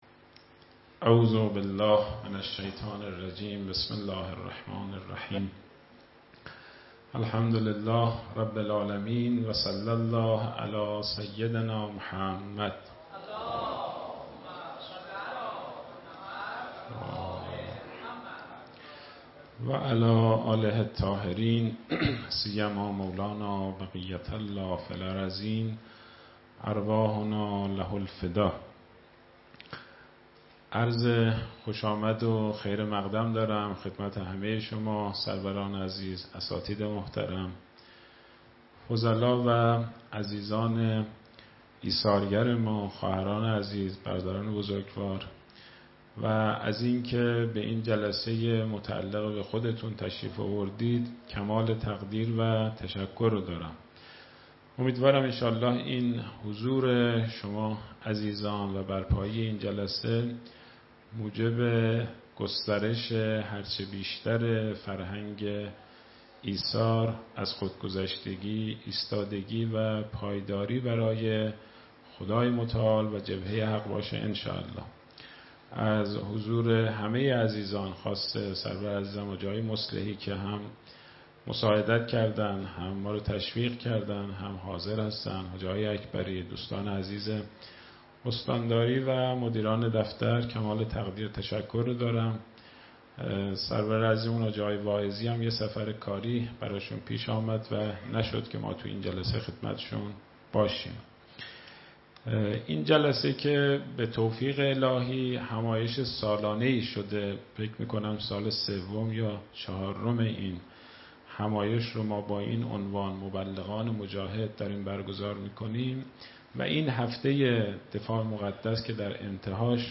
سخنرانی
در همایش" مبلغان مجاهد"